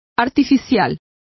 Complete with pronunciation of the translation of artificial.